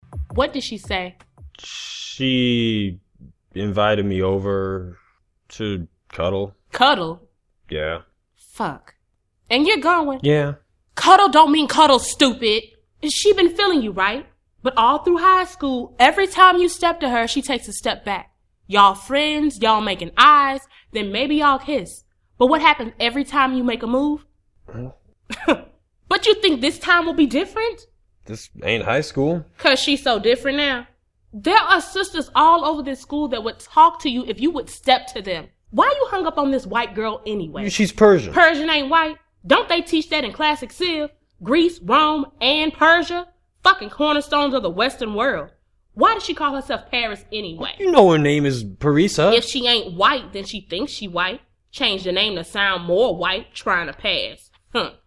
SPOKEN WORD - MPAACT Podcast Plays
Spoken-word-promo.mp3